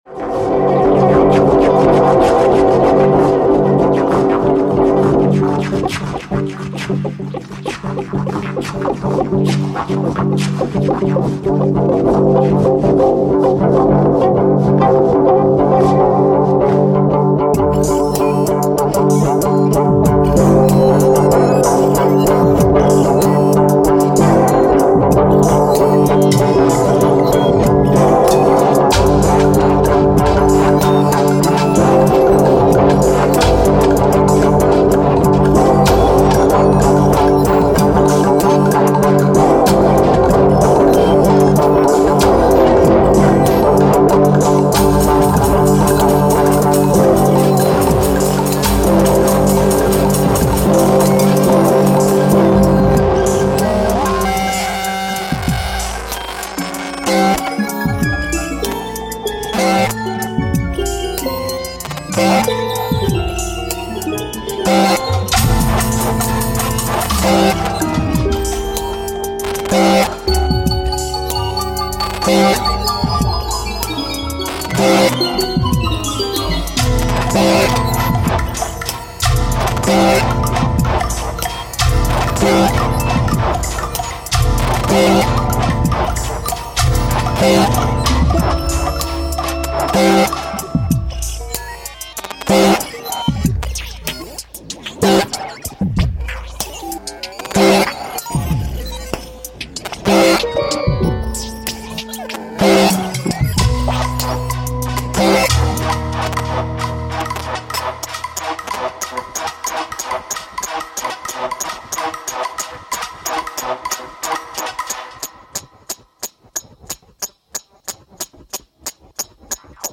Instant compositions by all musicians,
6 and 14 live at Le Triton